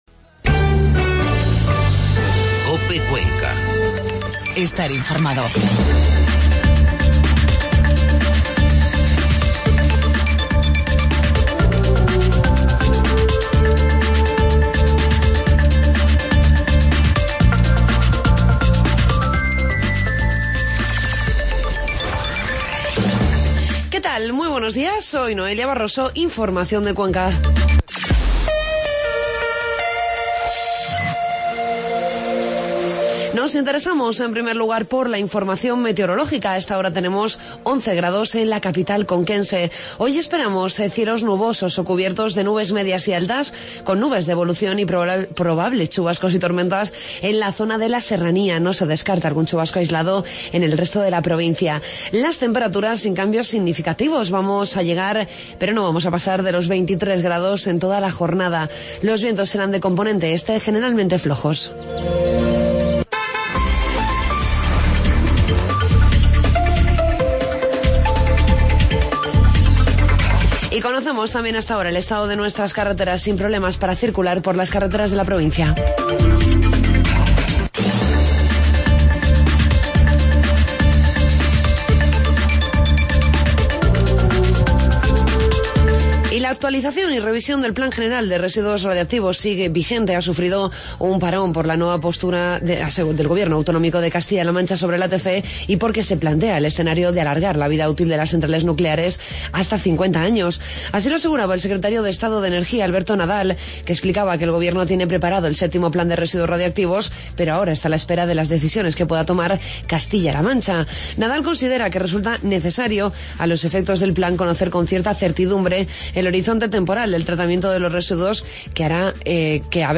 Informativo matinal miércoles 30 de septiembre